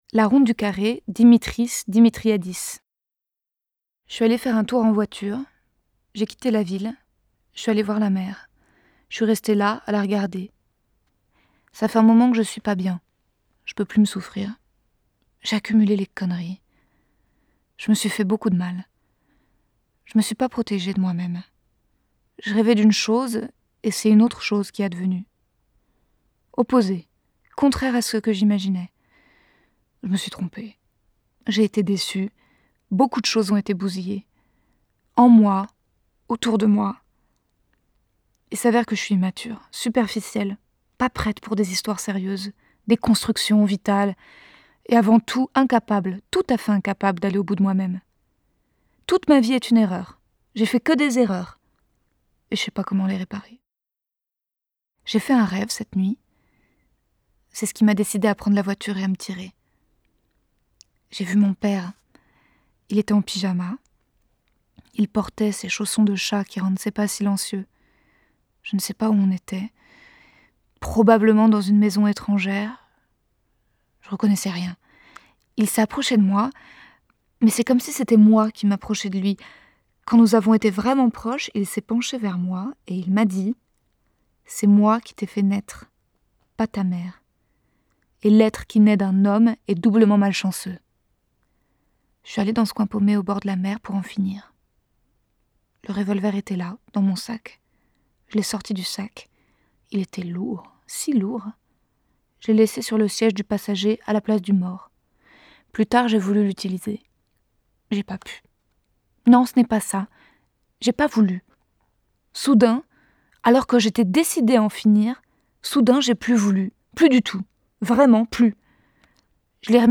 DEMO VOCALE